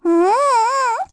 Lilia-Vox-Deny_kr.wav